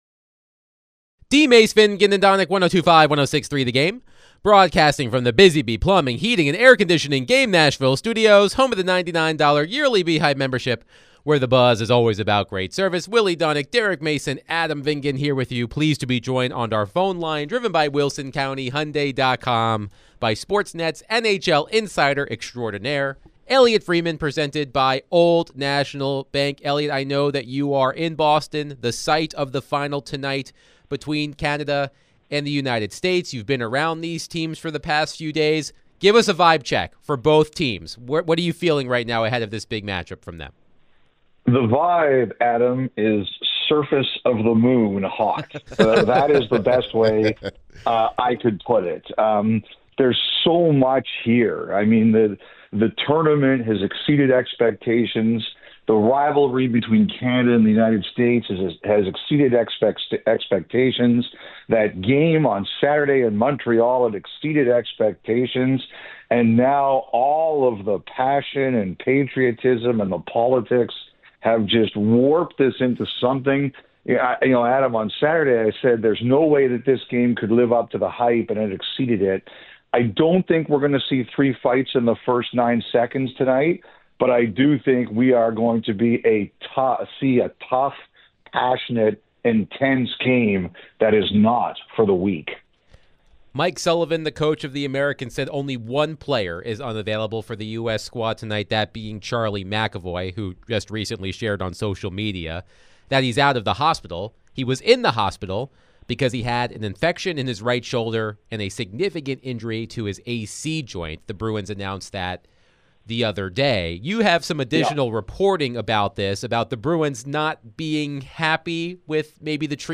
NHL Insider Elliotte Friedman joined DVD to discuss the 4 Nation tourney